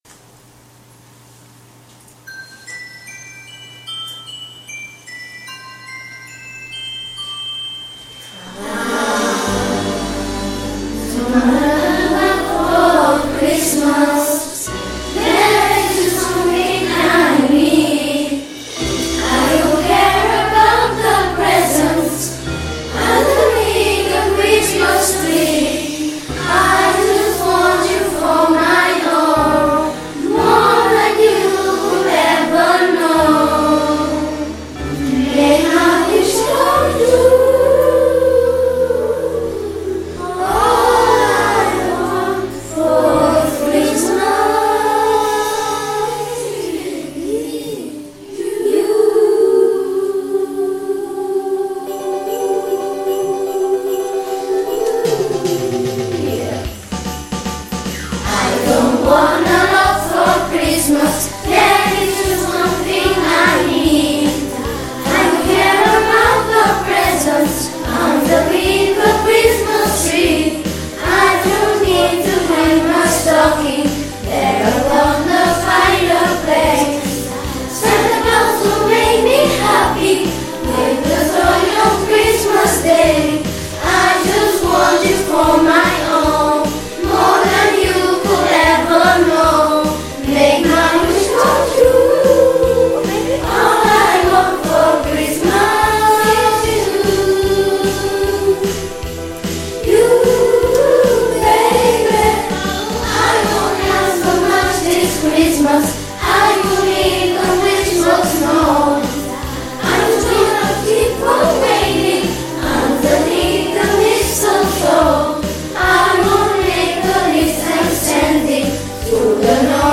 Els més grans de l’escola van cantar la cançó “ALL I WANT FOR CHRISTMAS”; i ho van fer